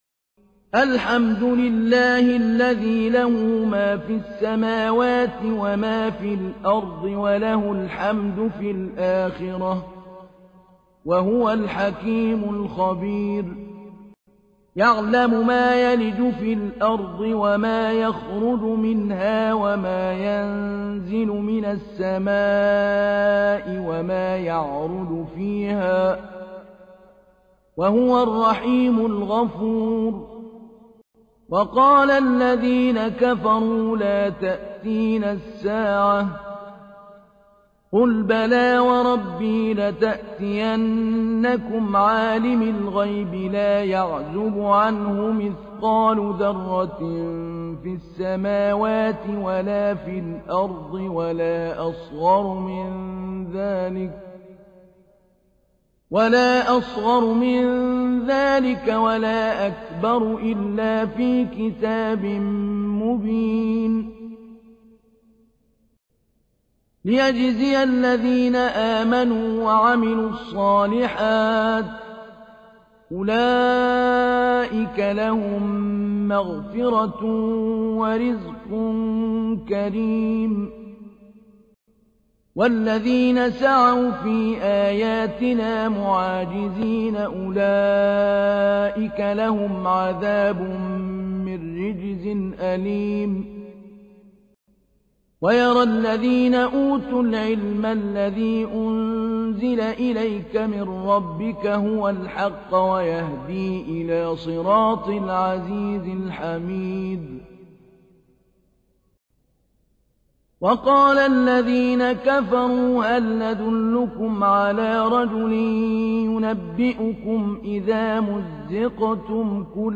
تحميل : 34. سورة سبأ / القارئ محمود علي البنا / القرآن الكريم / موقع يا حسين